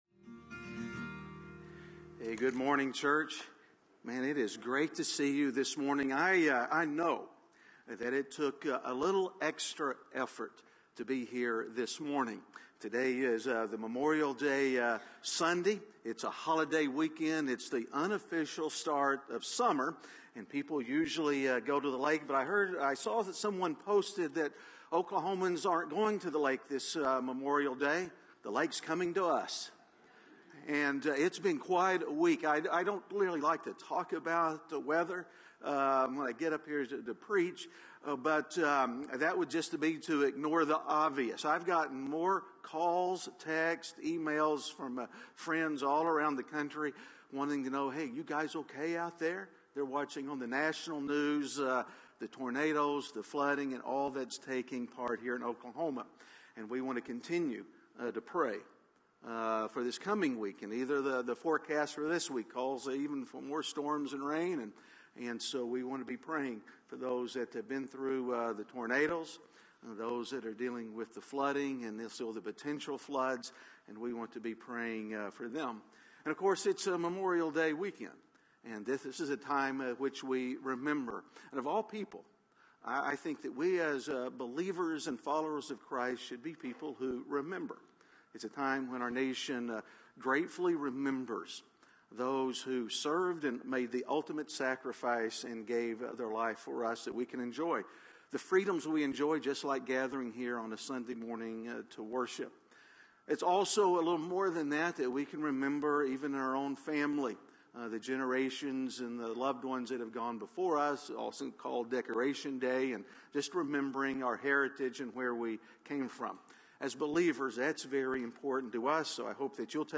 Missed a Sunday? You can go back and catch up on any of the sermons you missed.